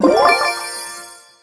pickup_spawn_03.wav